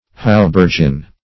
Haubergeon \Hau*ber"ge*on\ (h[add]*b[~e]r"j[-e]*[o^]n), n.